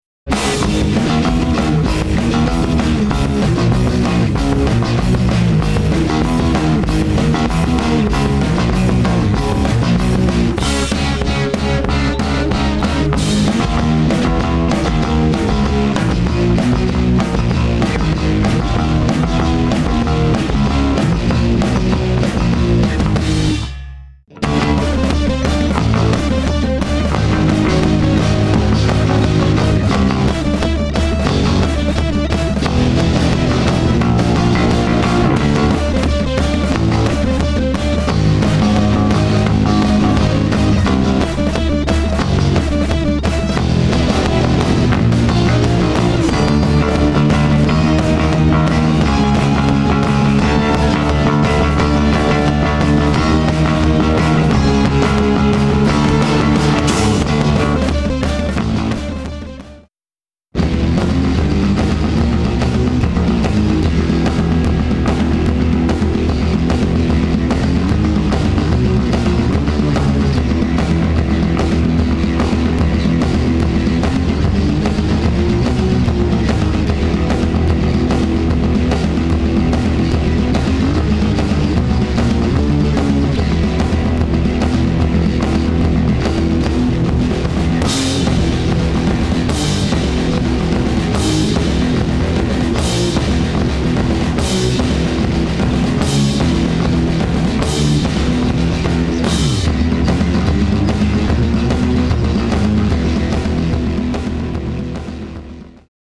Anger medley